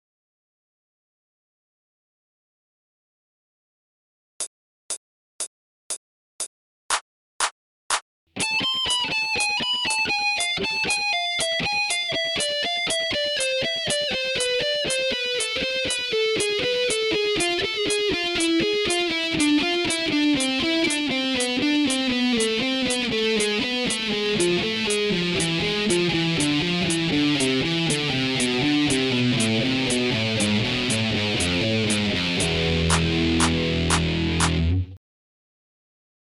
training phrase 2　ハーモニックマイナースケール〜下降フレーズ〜
training phrase 1同様、１弦２０フレットから６弦０フレットまでの下降フレーズです。
ただハーモニックマイナースケールの為、若干の違いがありますが、基本的にはtraining phrase 1と同じです。